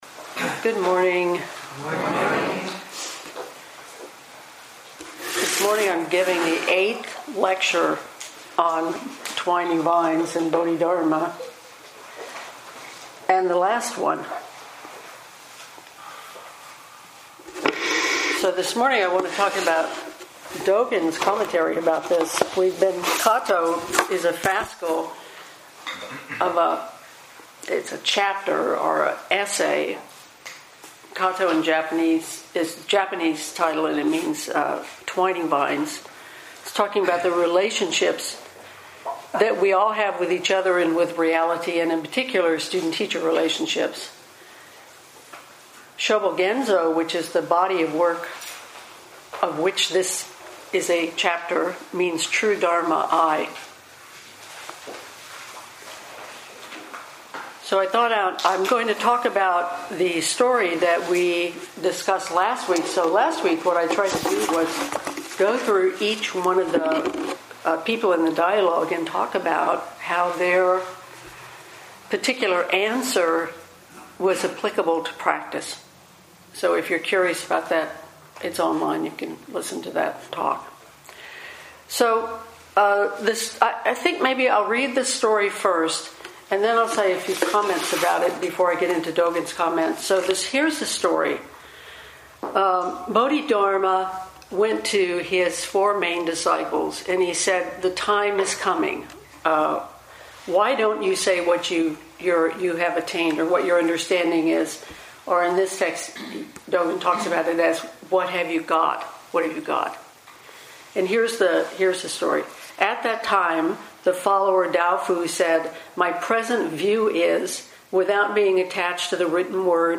2019 in Dharma Talks